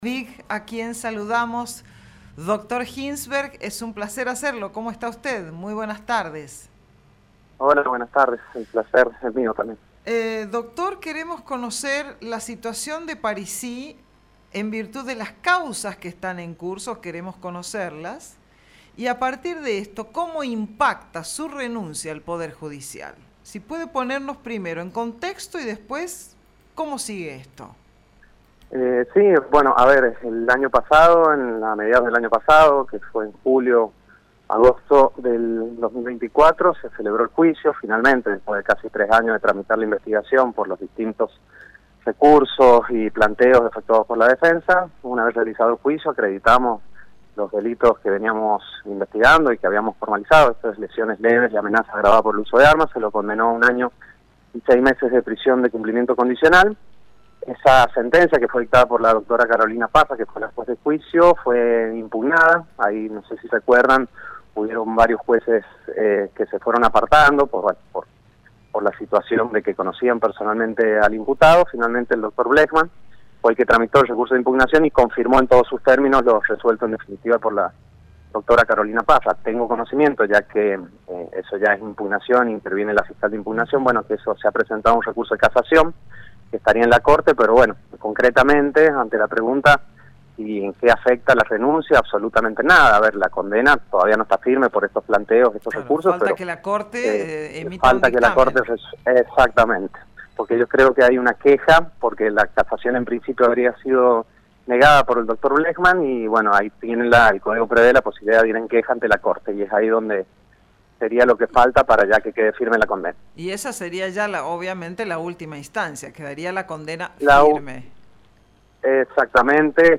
Finalmente, el juez Maximiliano Blejman tomó la posta y en febrero resolvió ratificar la condena ESCUCHÁ LA ENTREVISTA.